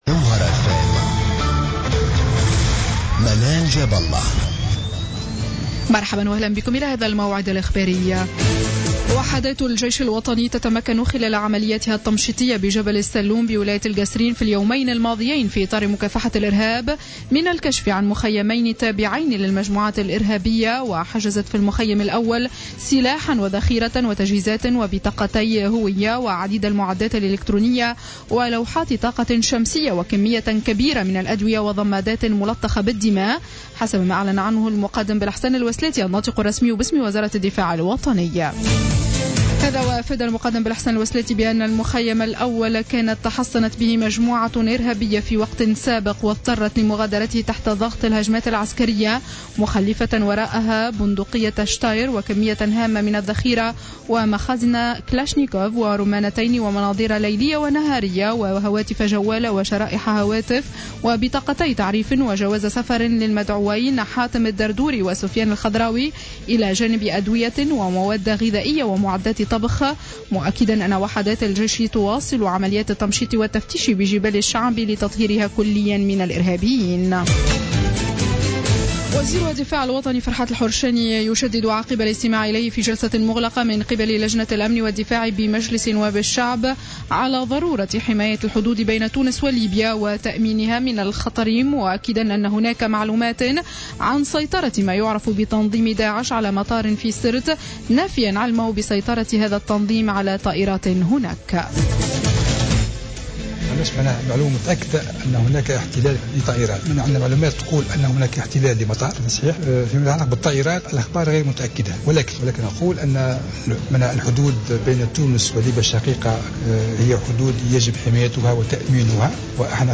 نشرة أخبار منتصف الليل ليوم الجمعة 5 جوان 2015